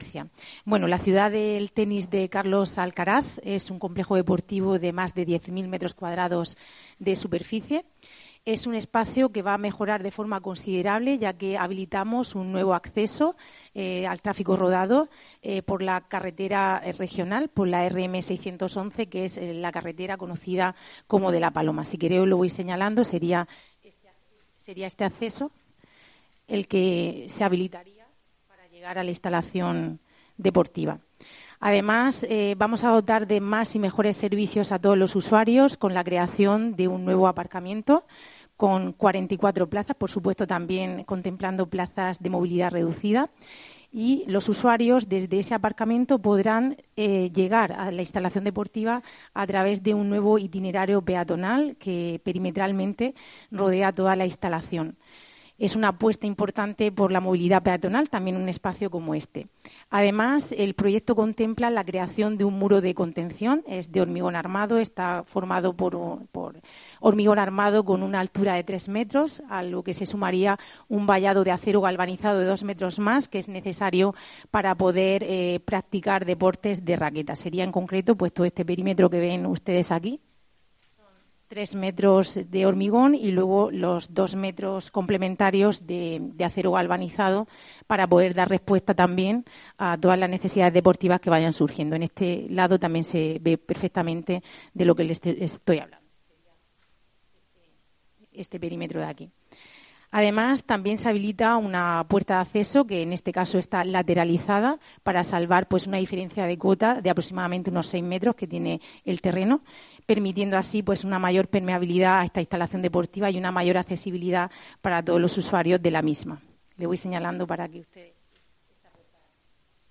Así lo ha anunciado esta mañana el alcalde Ballesta durante la presentación de la Fase I del proyecto estratégico deportivo, en una rueda de prensa en la que también han estado presentes la vicealcaldesa y concejal de Fomento y Patrimonio, Rebeca Pérez; el concejal de Deportes, Miguel Ángel Noguera, y la presidenta de la Junta Municipal de El Palmar, Verónica Sánchez.
Rebeca Pérez, vicealcaldesa de Murcia, presenta la primera fase del proyecto